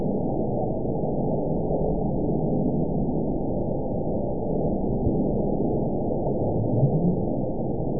event 914326 date 05/05/22 time 00:37:42 GMT (3 years ago) score 9.58 location TSS-AB04 detected by nrw target species NRW annotations +NRW Spectrogram: Frequency (kHz) vs. Time (s) audio not available .wav